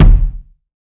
KICK.123.NEPT.wav